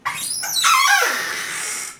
Chirrido de la puerta de un armario
puerta
chirriar
Sonidos: Hogar